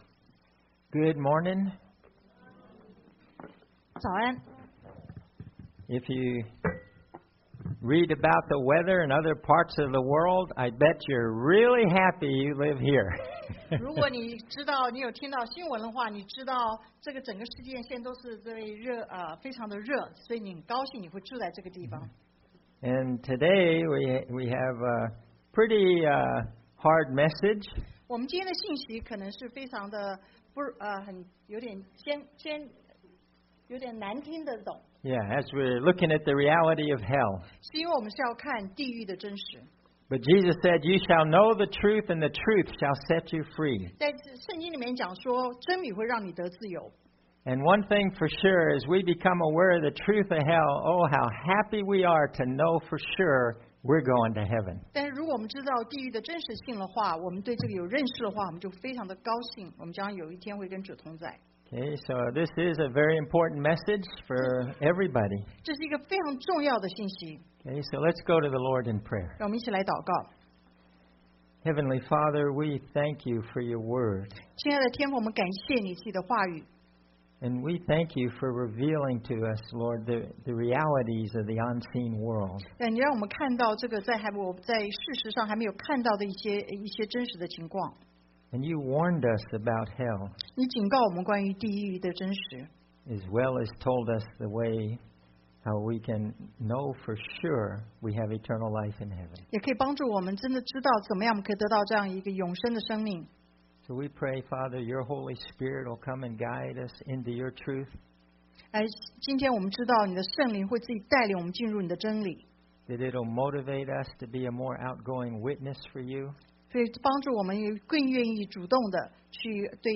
Luke 16:19-31 Service Type: Sunday AM Bible Text